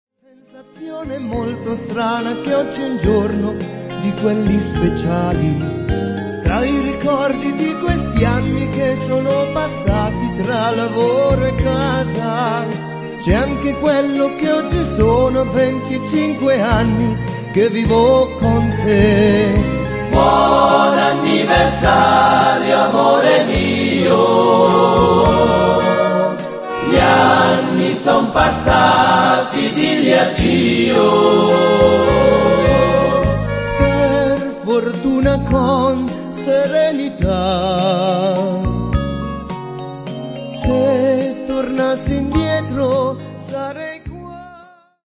valzer lento